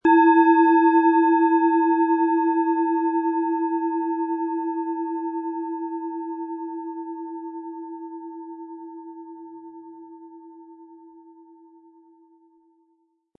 Hopi Herzton
Es ist eine von Hand gearbeitete tibetanische Planetenschale Hopi-Herzton.
Der Schlegel lässt die Schale harmonisch und angenehm tönen.
SchalenformBihar
MaterialBronze